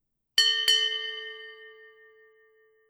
ShipBellTwoChimes – Here There Be Mermaids!
2bells.wav